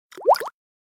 water-drop-short_24897.mp3